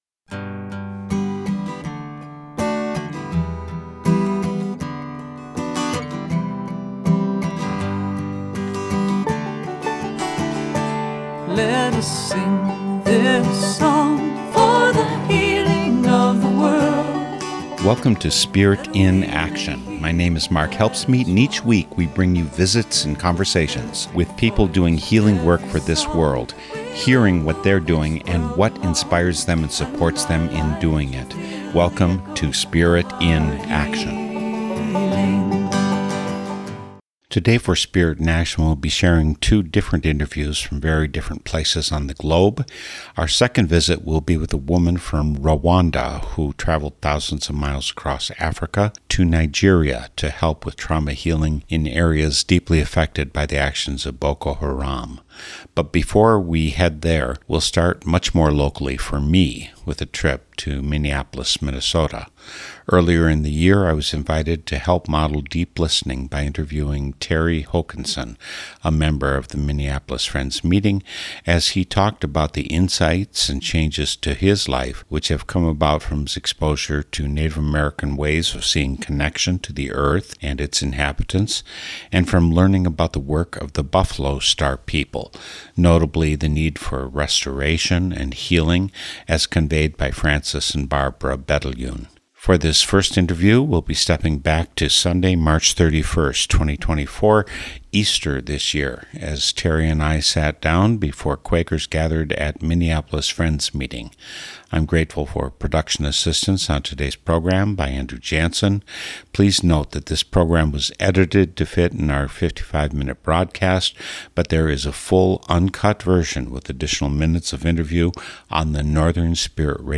This interview took place before the members of Minneapolis Friends Meeting on Easter Sunday 2024.